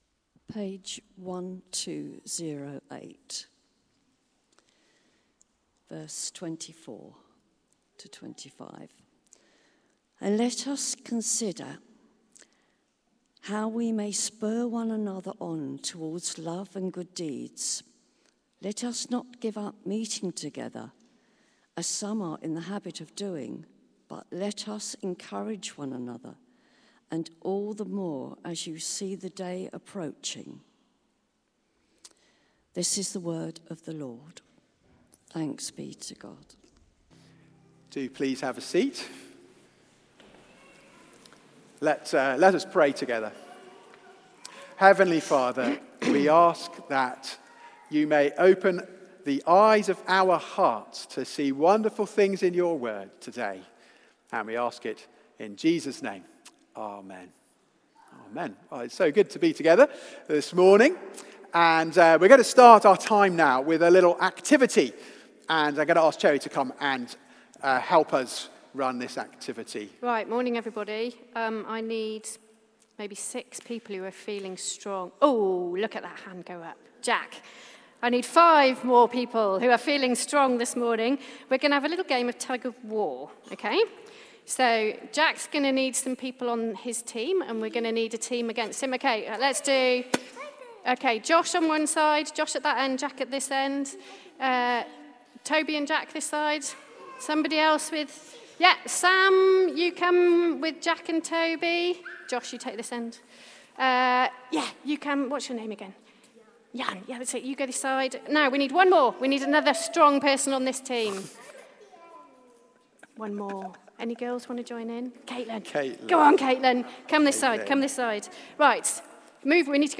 Media for Service (10.45) on Sun 27th Aug 2023 10:45
Series: God's Grace for the Christian Life Theme: The Gift of God's Family Sermon